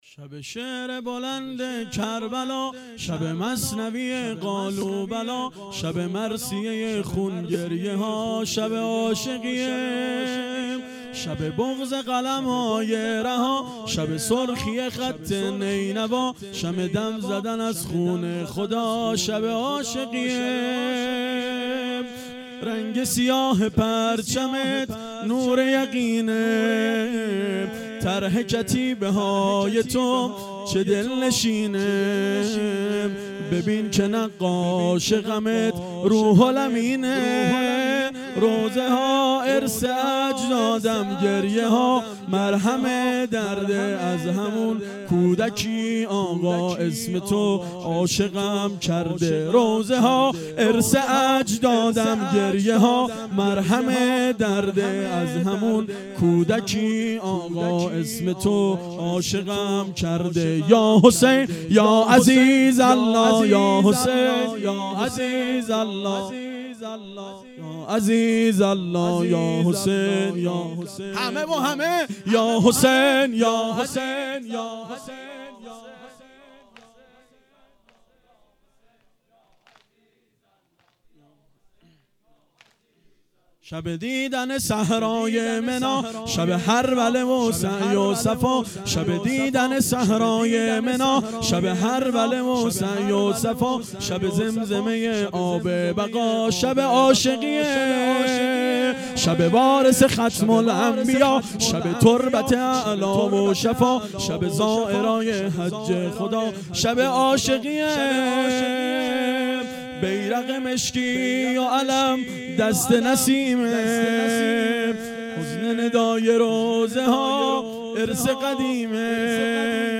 واحد تند شب هفتم